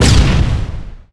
SFX pickup_bomb.wav